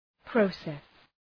Προφορά
{‘prɒses, ‘prəʋses}